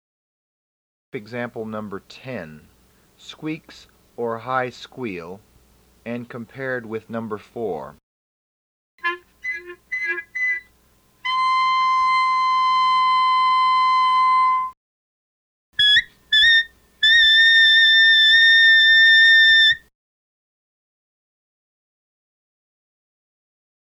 SQUEAKS/HI SQUEAL – EXAMPLE #10 is analogous to #4
STOPPED/INTENSE AIR – EXAMPLE #11 is analogous to #5
THIN TONE/SHARP PITCH – EXAMPLE #12 is analogous to #6